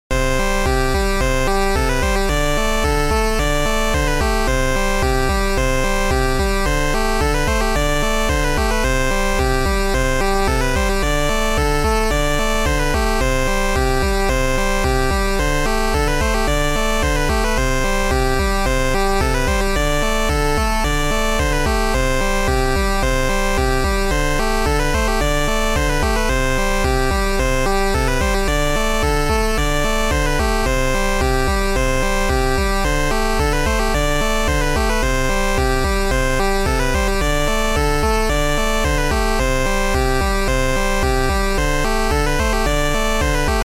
一言でまとめると、ファミコンのぴこぴこした音が好きでたまらん。
ということで、chiptuneってどうやって導入するんだ？　とぐぐった結果、簡単に作成できるソフトをDLしまして、１分くらいで打ち込んだのがこれ↓
♪１（適当に押し込んだノイズがめっちゃうるさいので注意）